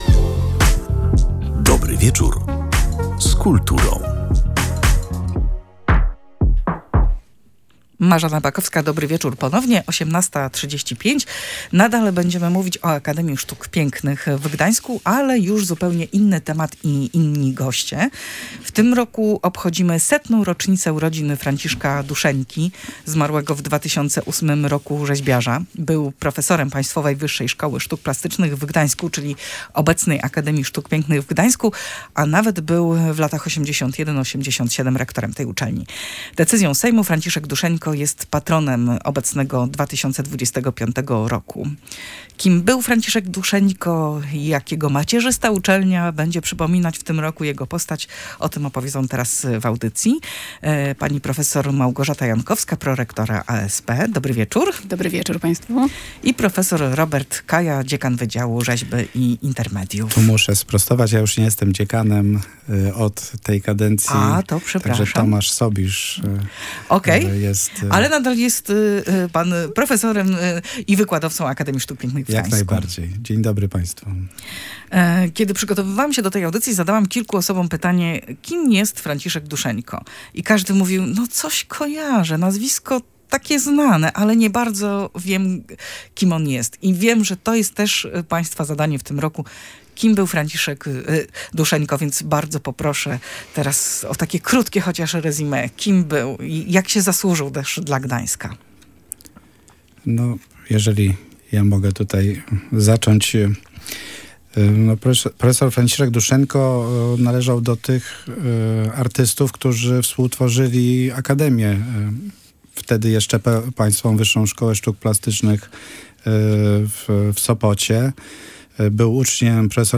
O wystawie oraz dziedzictwie profesora Duszeńki w kontekście współczesnej sztuki i edukacji artystycznej rozmawiali z red.